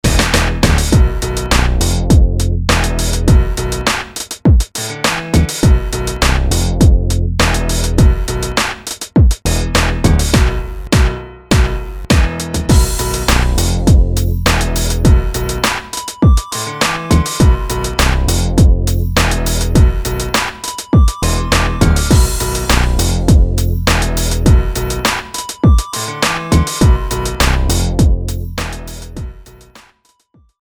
full mix, 60 & 30 edit version include